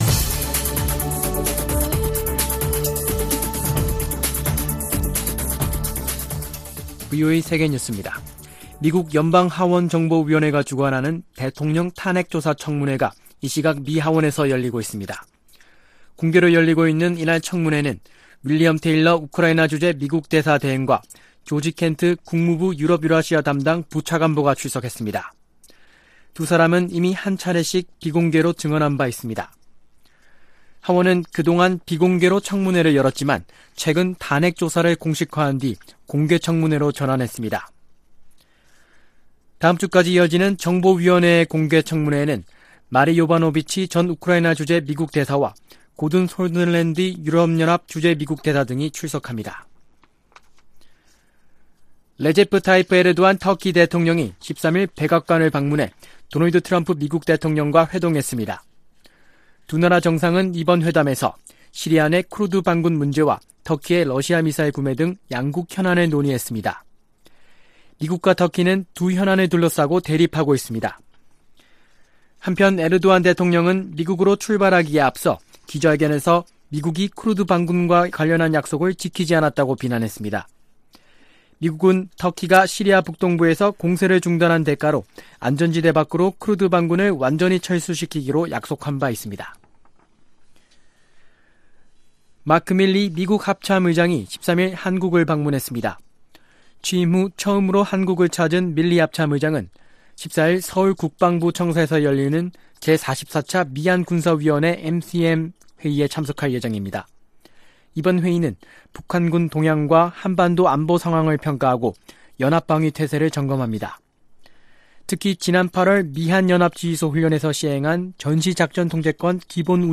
VOA 한국어 아침 뉴스 프로그램 '워싱턴 뉴스 광장' 2019년 11월 14일 방송입니다. 지난주 열린 ‘모스크바 비확산 회의’ 에서 미국과 북한 대표가 만났다고 토마스 컨트리맨 전 미 국무부 차관 대행이 밝혔습니다. 미국은 북한을 대화의 장으로 나오게 하기 위해서 ‘미치광이’ 전략을 사용했다고 니키 헤일리 전 유엔주재 대사가 밝혔습니다.